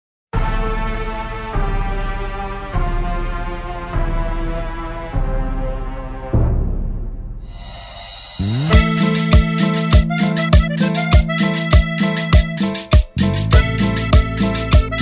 Cultural, Latin